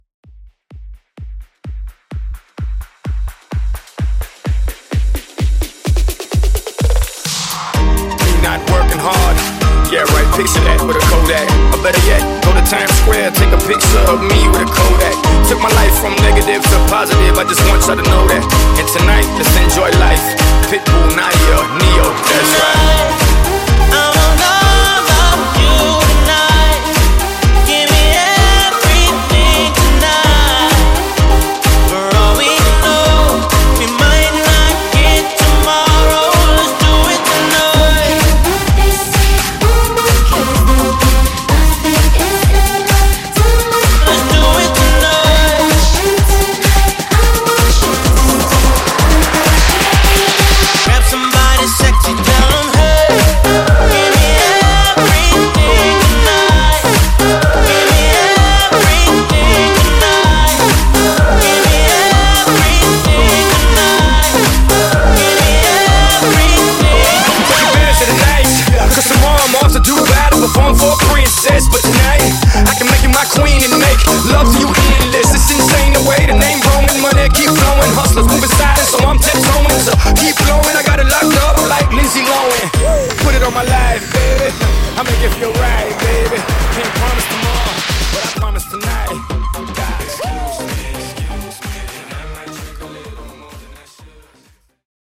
House Bootleg)Date Added